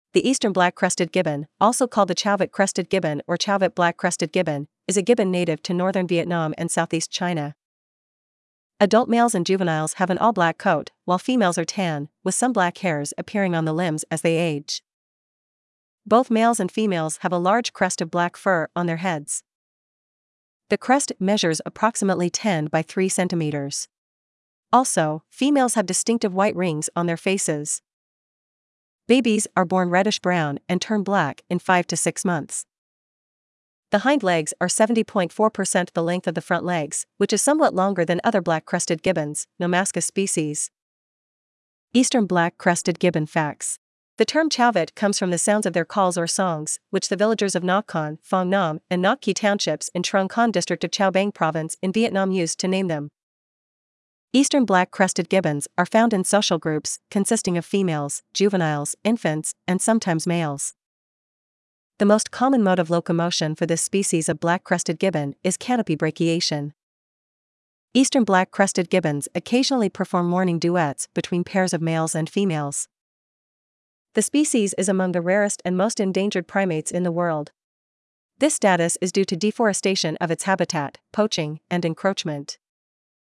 Eastern Black-Crested Gibbon
• Eastern black-crested gibbons occasionally perform morning duets between pairs of males and females.
eastern-black-crested-gibbon.mp3